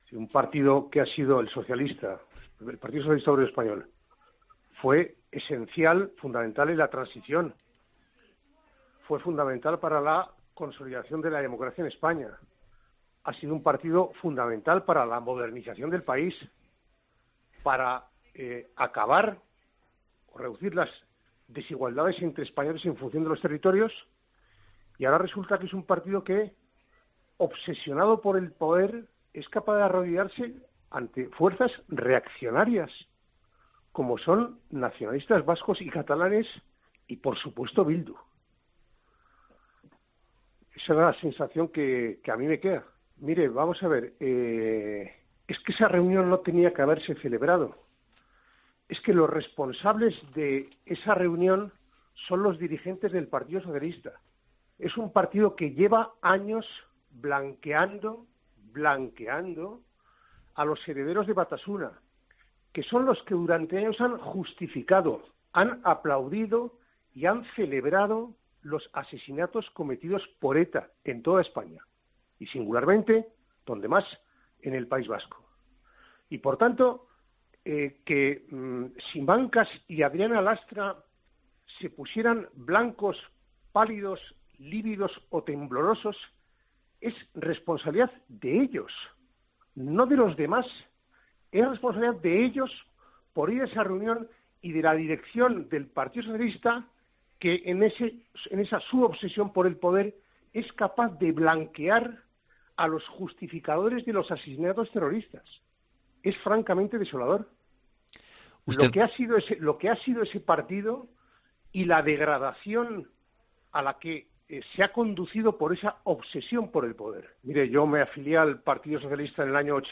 En una entrevista este jueves en COPE